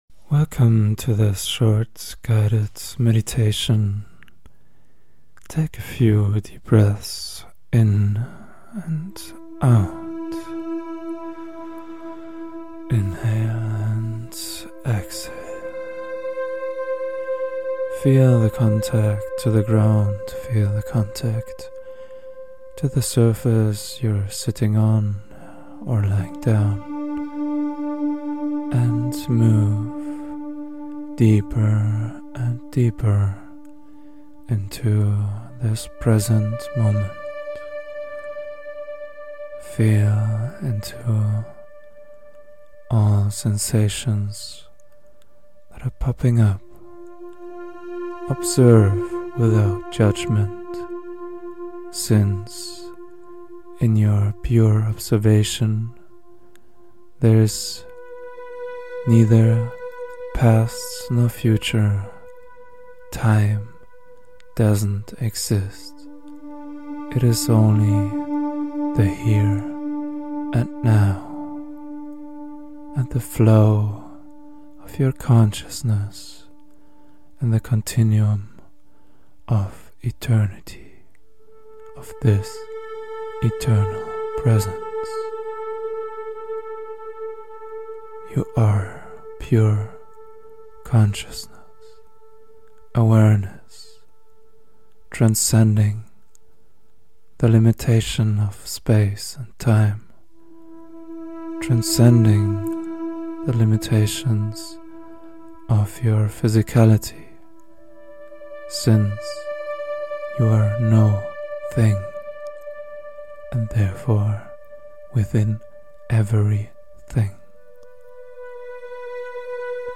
This short guided Meditation guides sound effects free download